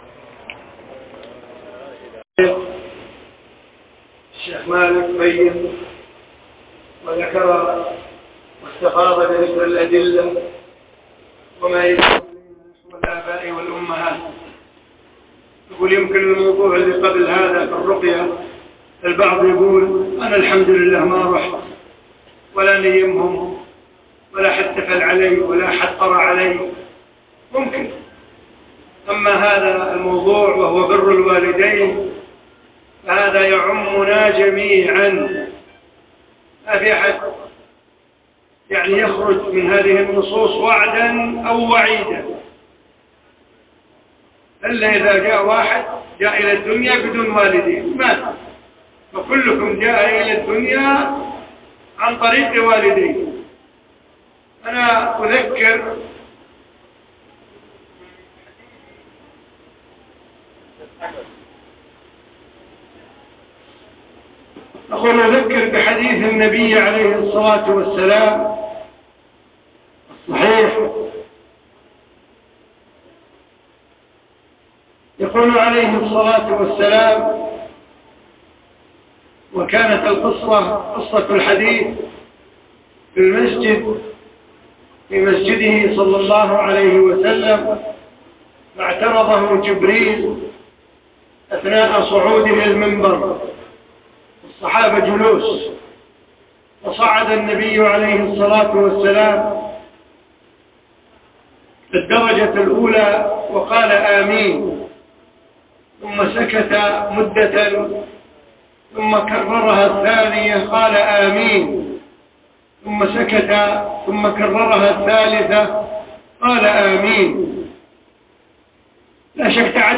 كلمة في حج عام 1432